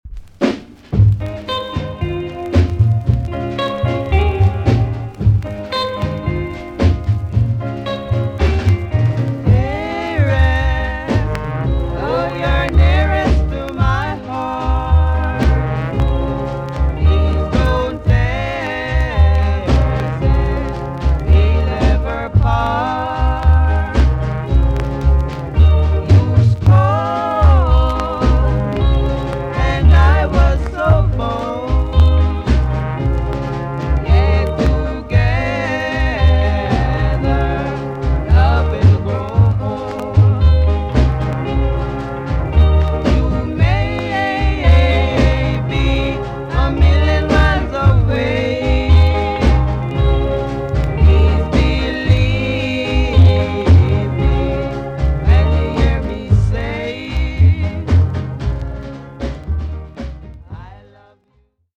TOP >SKA & ROCKSTEADY
VG+ 少し軽いチリノイズが入ります。
UK , NICE BALLAD TUNE!!